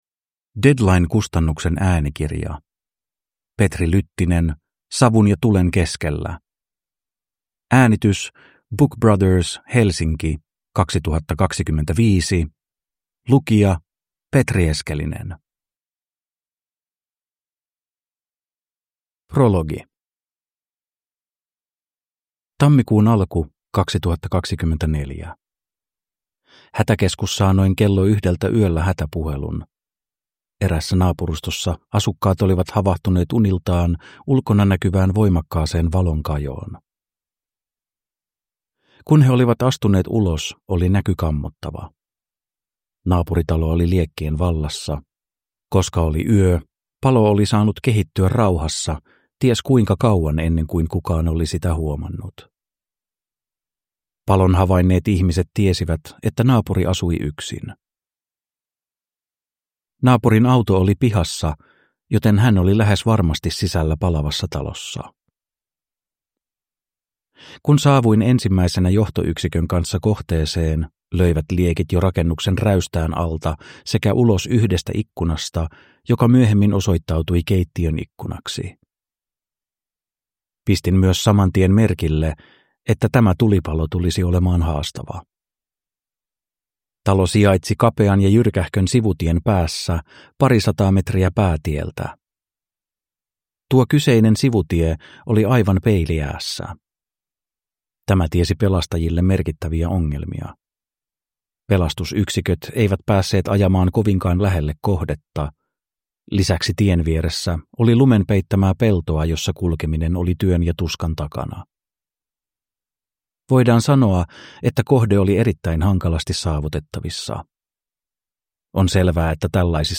Savun ja tulen keskellä – Ljudbok
Memoarer & biografier Njut av en bra bok